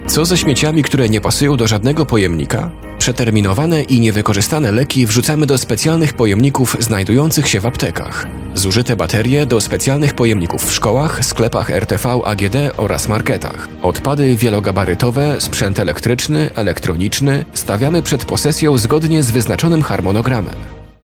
To fragment filmu informacyjnego, który przygotował urząd miasta.